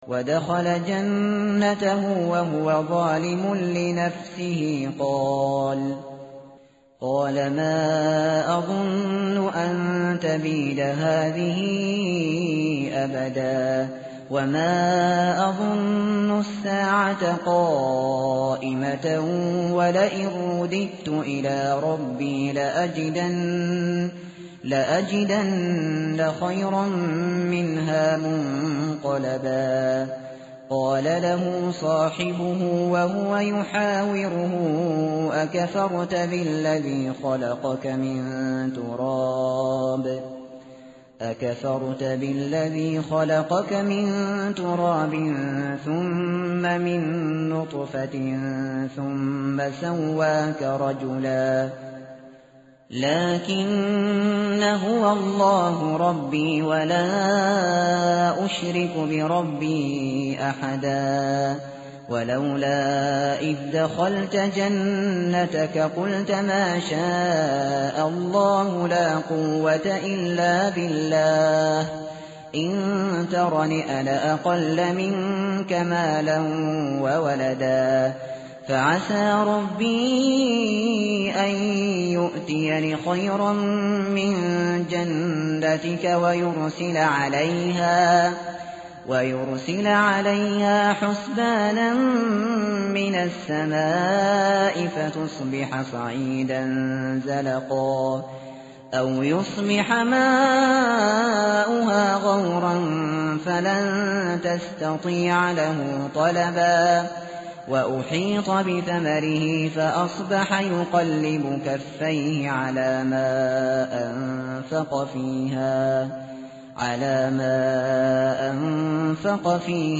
tartil_shateri_page_298.mp3